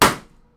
balloon-pop-01.wav